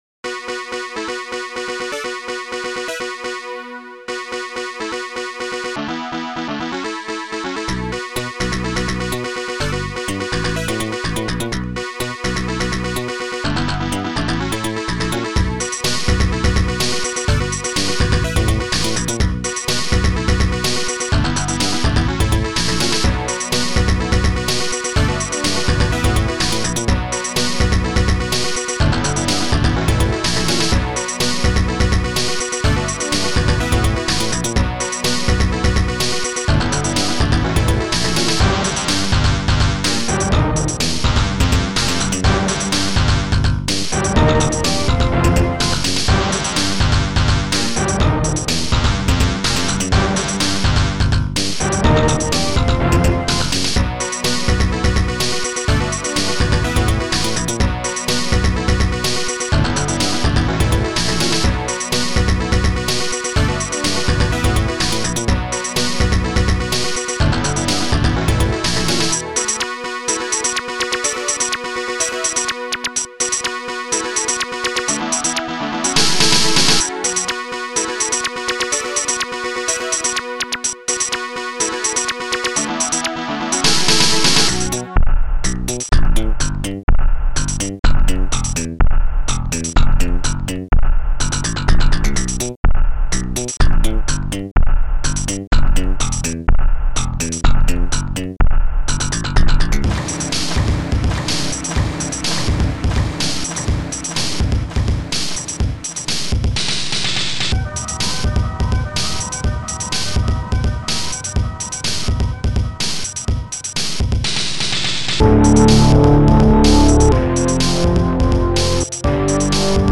AMOS Music Bank  |  1991-01-29  |  97KB  |  2 channels  |  44,100 sample rate  |  3 minutes, 4 seconds
AMOS Music Bank